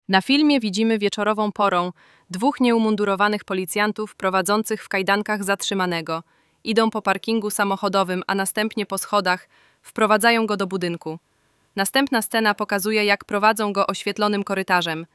Audiodeskrypcjafilmu.mp3